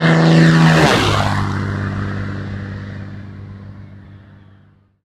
flyby.wav